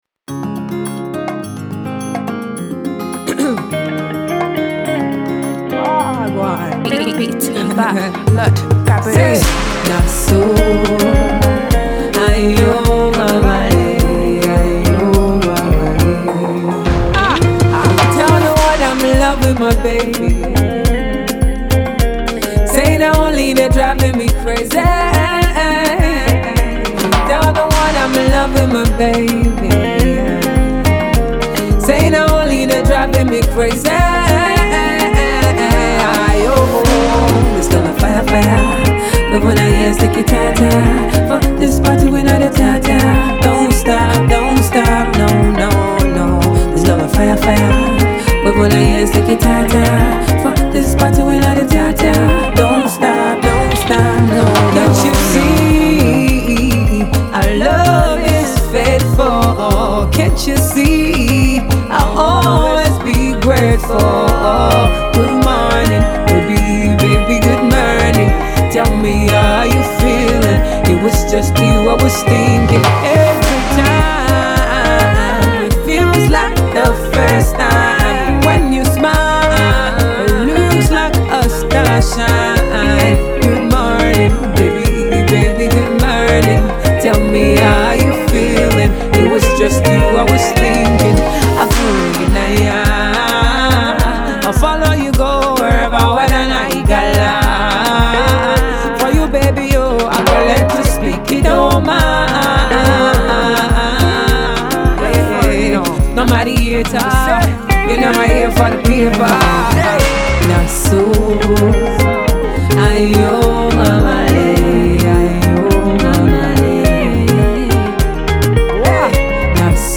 Nigerian female Dancehall Singer